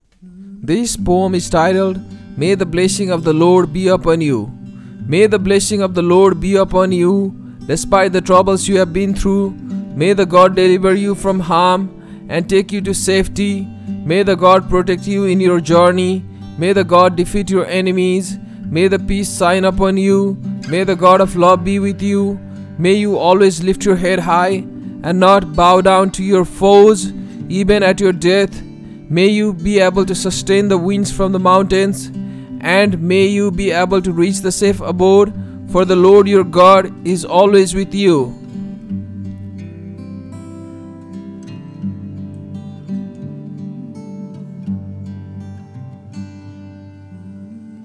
by | Sep 24, 2024 | Poem | 1 comment
But it is better to use a different tone for different themed poems; that may sound more interesting and fun!